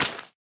wet_grass4.ogg